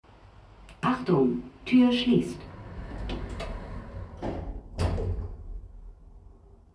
Januar 2022 In Bezug auf Tonaufnahmen Kategorien: Tonaufnahmen Schlagwörter: Aufzug , Fahrstuhl , Geräusche , öffentlicher Nahverkehr , ÖPNV Schreibe einen Kommentar